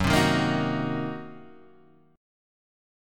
F# 6th Flat 5th